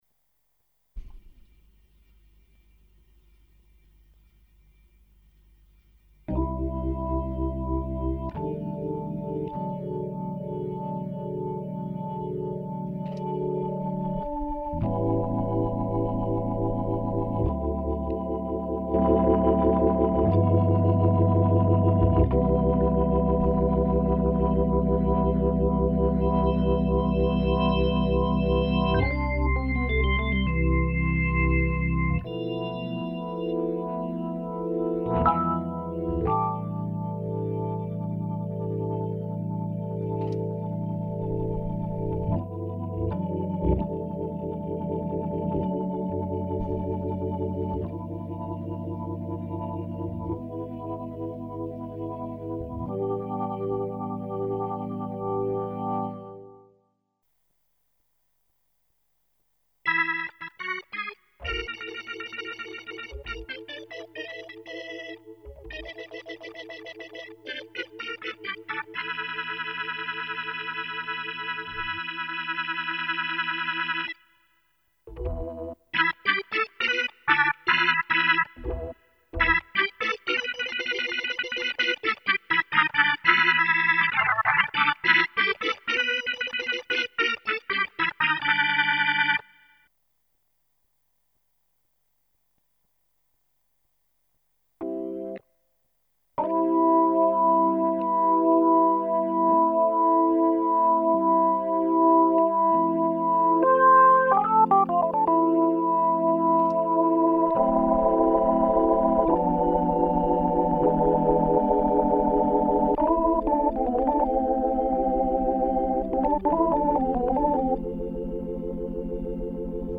I`ve been working on a big sample + prog/combi set of Hammond sounds lately.
2.preset raping